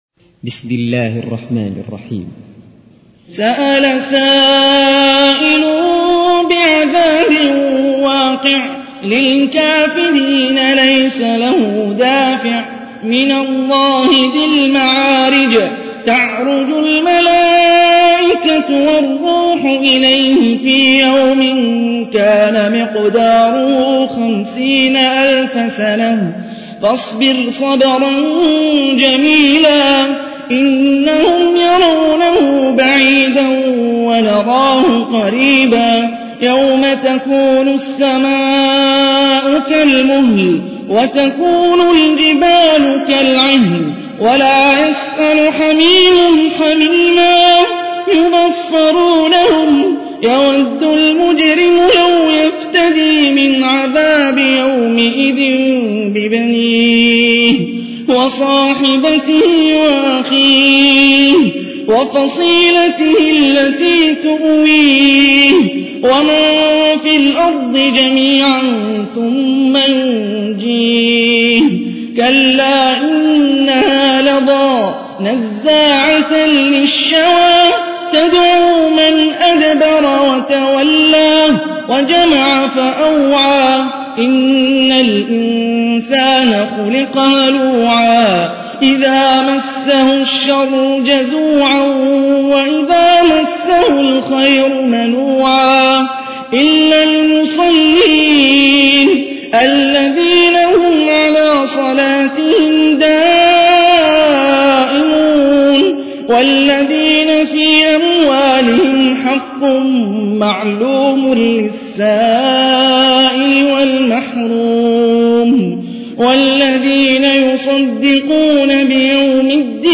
تحميل : 70. سورة المعارج / القارئ هاني الرفاعي / القرآن الكريم / موقع يا حسين